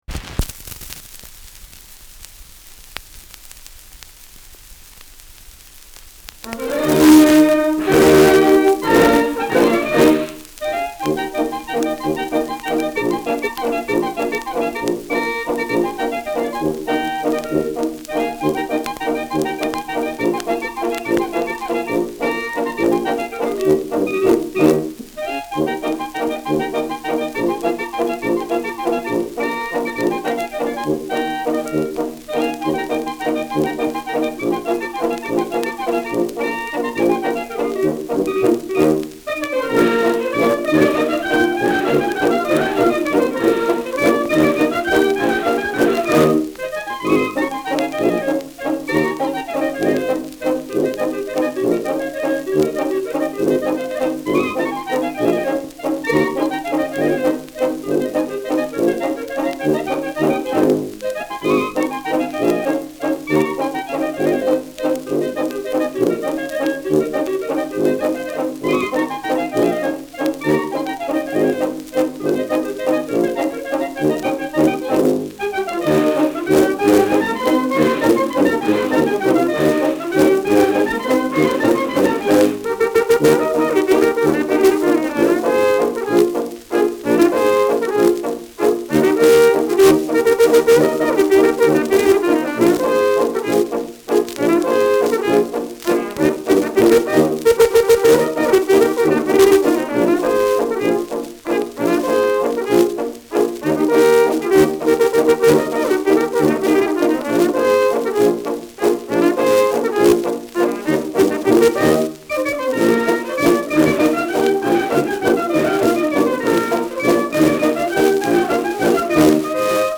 Schellackplatte
Abgespielt : Teils verzerrt
Kapelle Durlhofer (Interpretation)